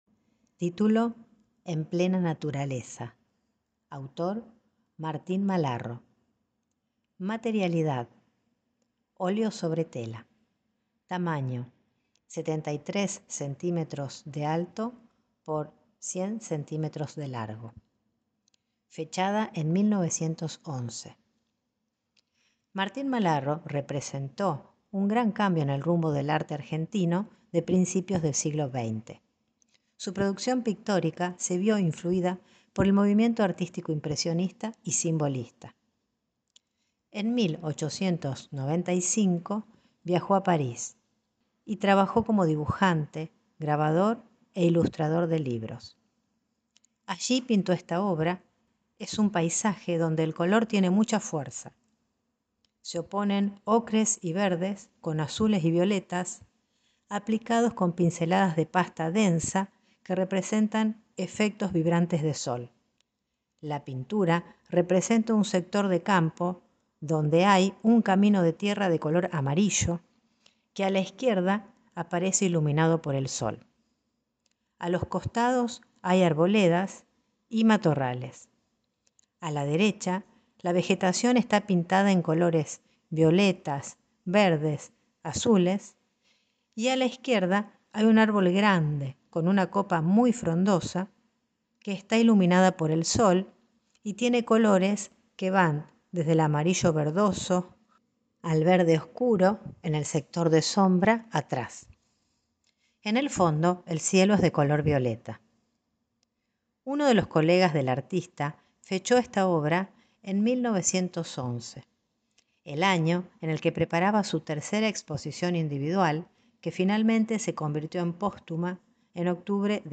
Audiodescripciones y Audioguías